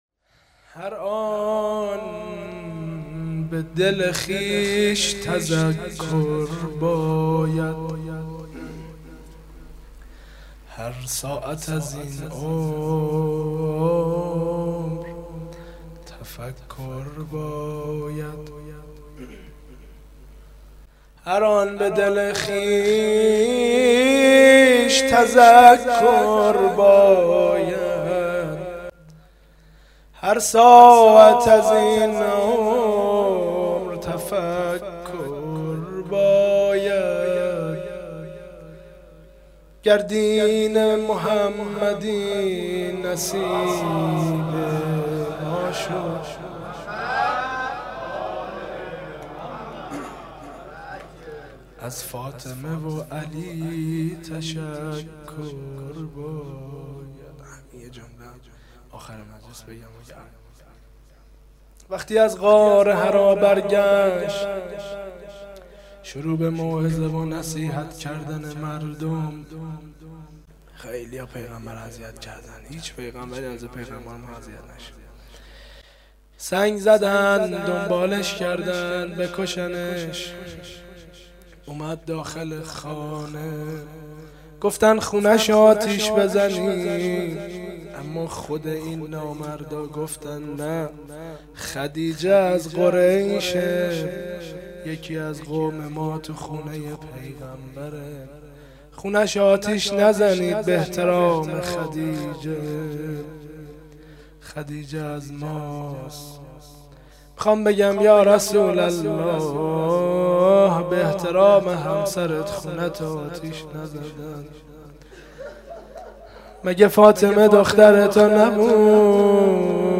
جشن مبعث پیامبر اعظم (ص)؛ روضه (توسل پایانی)
روضه‌ی خانگی